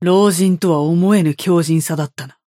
Yamato voice line - Unbelievable strength for an old man.